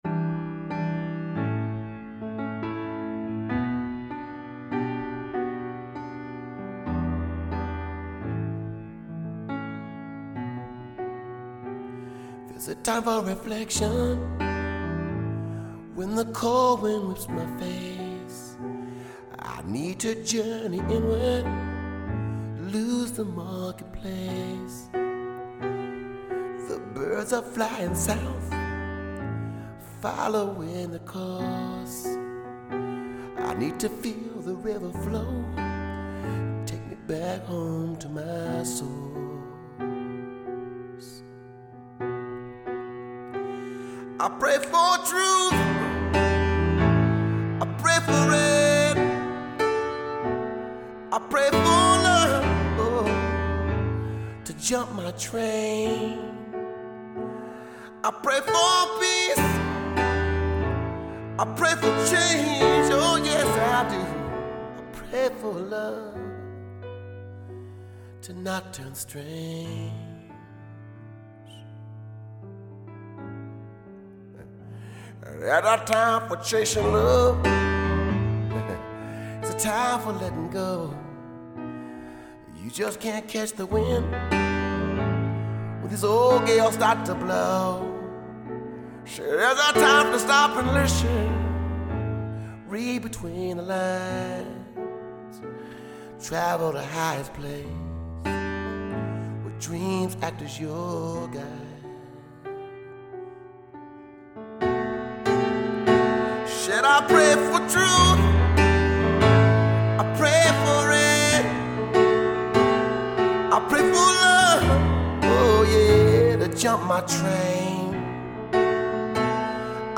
Emotional driving ballad Ml vox, grand piano